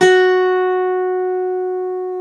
Index of /90_sSampleCDs/Club-50 - Foundations Roland/GTR_xAc 12 Str/GTR_xAc 12 Str 1
GTR X12 ST0G.wav